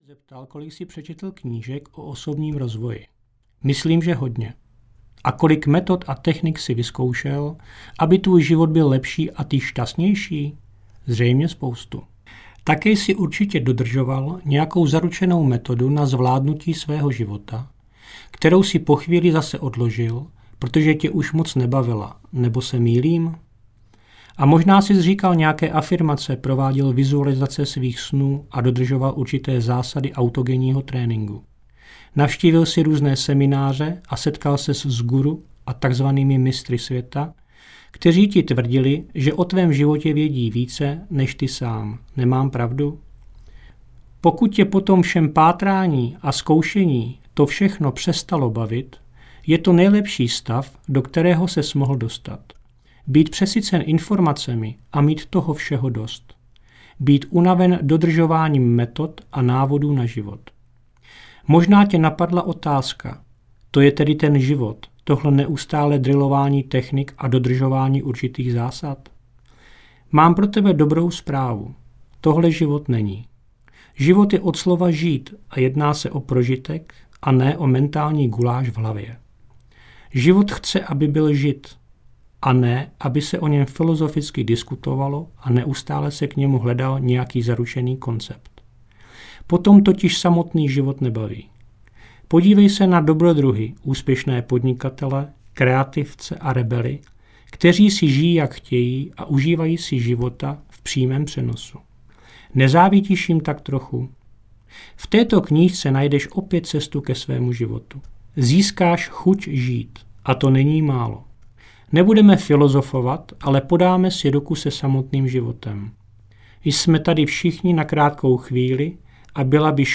Buď sám sebou a jdi vlastní cestou audiokniha
Ukázka z knihy